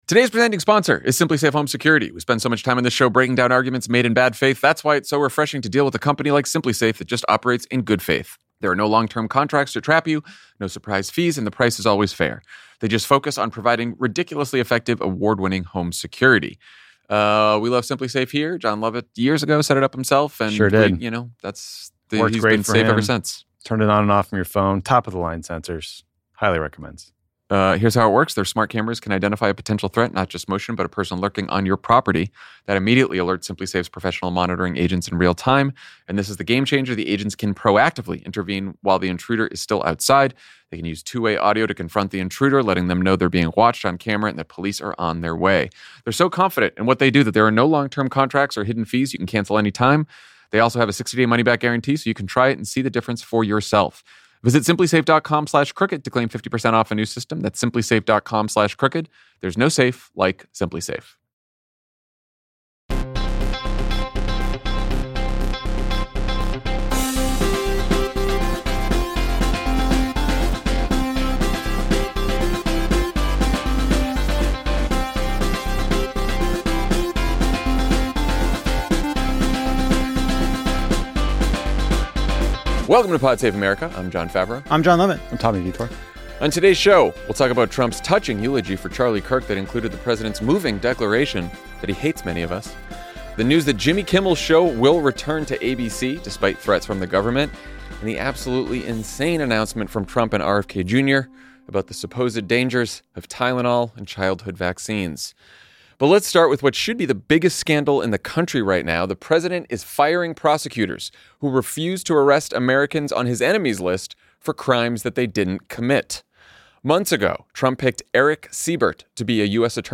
Then, Sen. Elizabeth Warren stops by the studio to talk to Lovett about the Democratic Party's impending government shutdown fight.